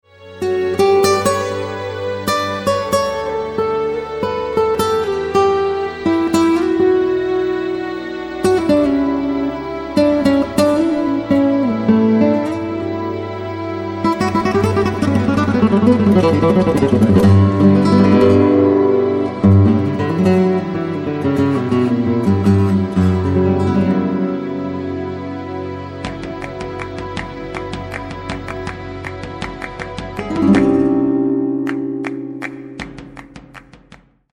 Guitar etc. , Progressive Metal